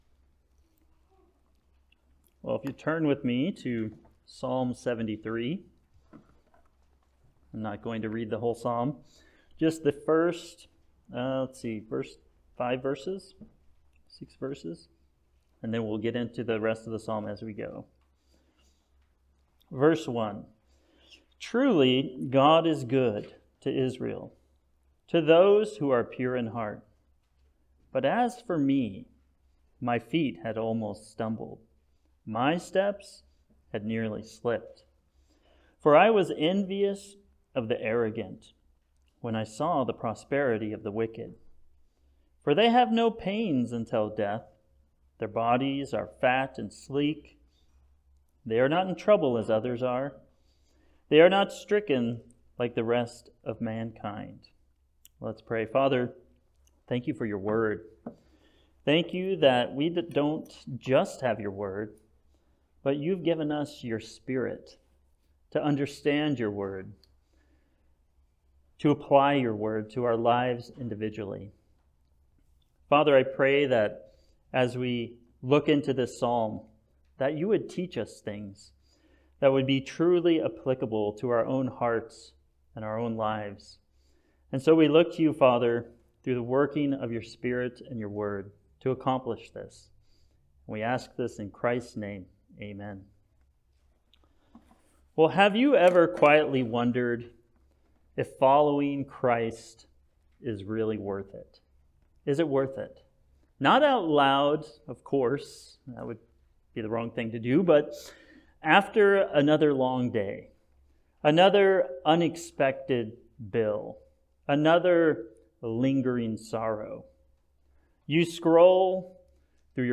Passage: Psalm 73 Service Type: Sunday Service